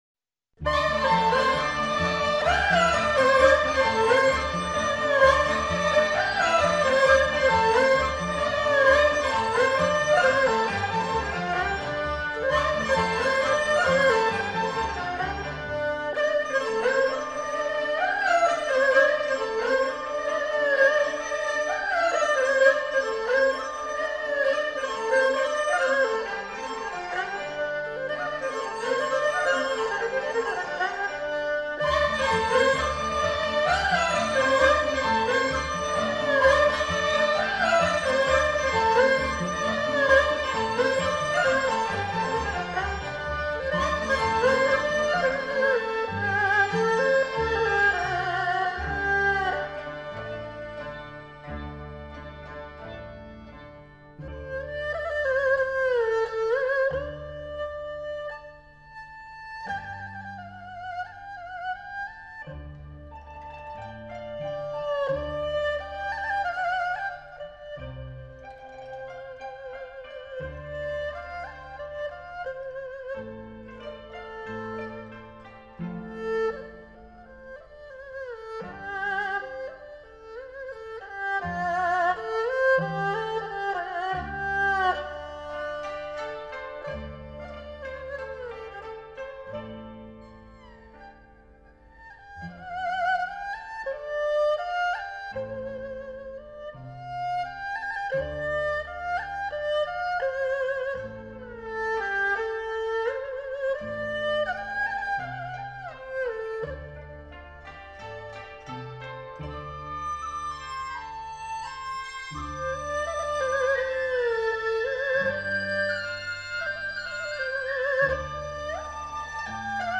乐曲表现了人民军队与老百姓之间的鱼水之情，音乐情绪乐观，曲调朴实亲切。